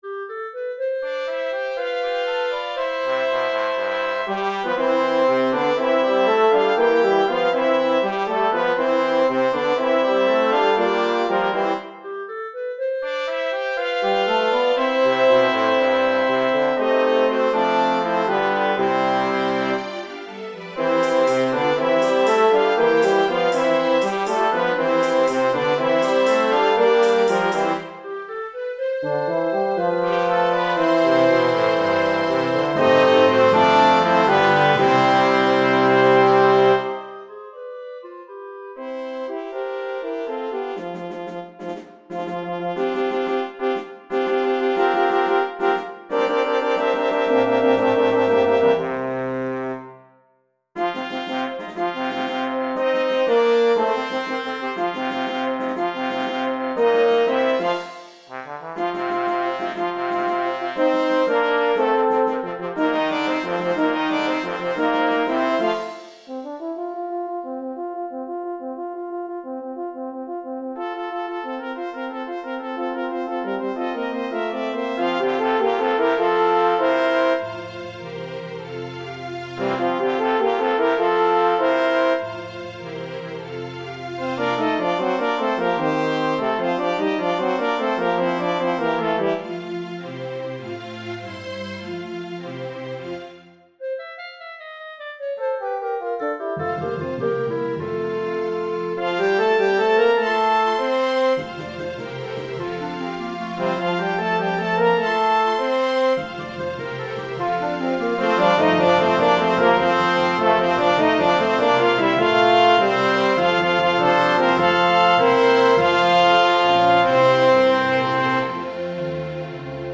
2. Overture